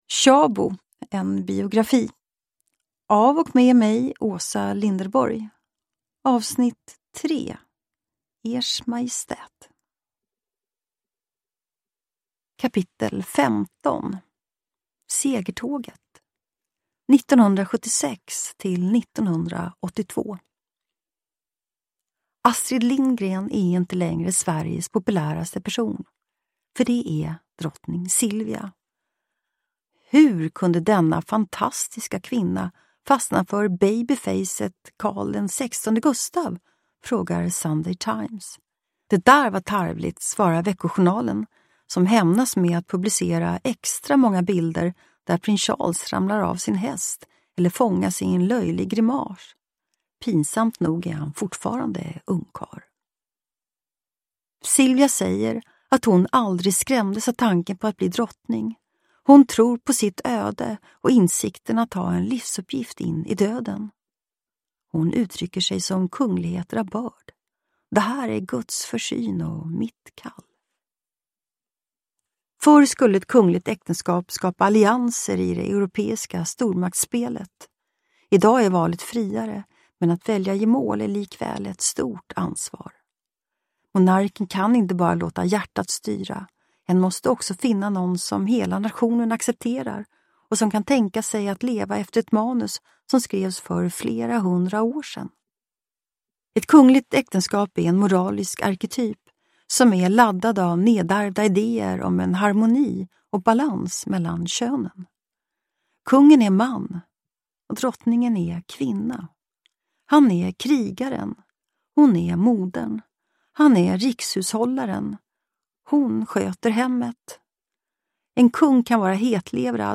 Uppläsare: Åsa Linderborg
Ljudbok